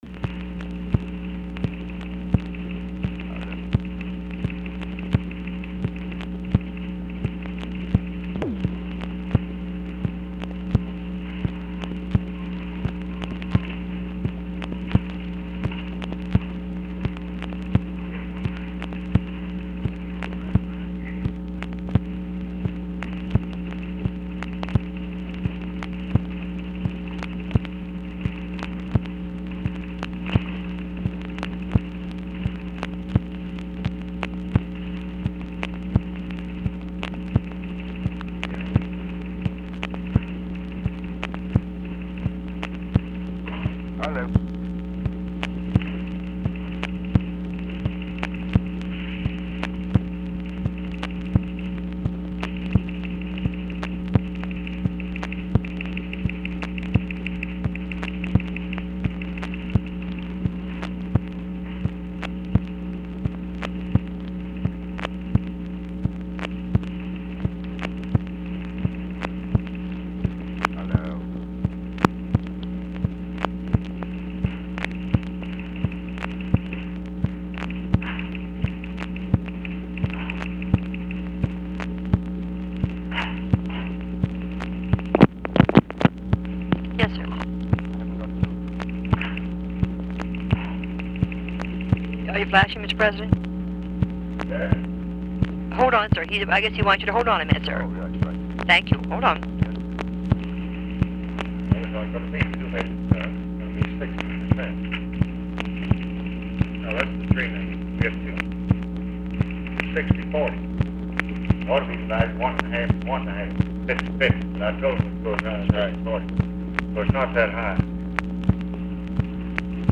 Conversation with B. K. NEHRU, OFFICE NOISE and OFFICE CONVERSATION, February 2, 1967
Secret White House Tapes